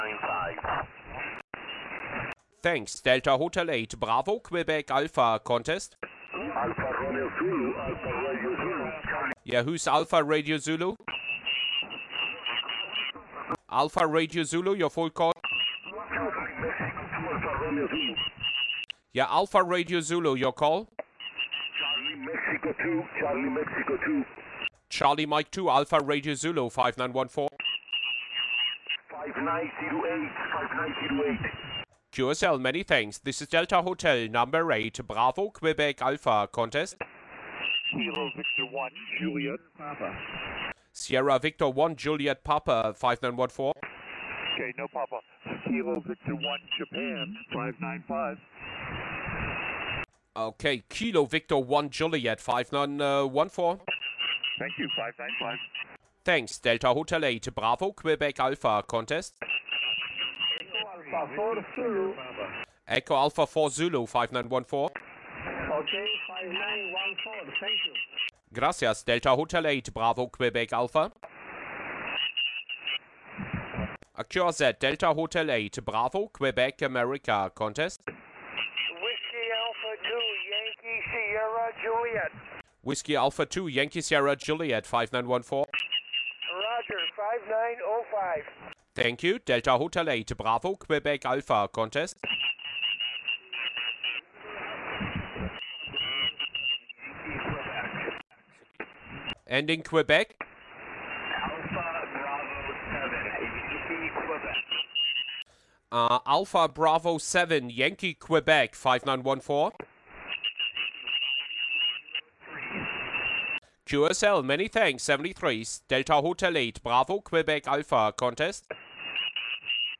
While Sunday had the better conditions I had the feeling there should have been more QSOs but it seems everybody was on 10 m 😉 and I also had lots more QRM on Sunday compared to Saturday which made it difficult to catch certain multipliers or work a pile-up with weaker stations …
Working NA on Sunday afternoon with lots of QRM (8:30 min):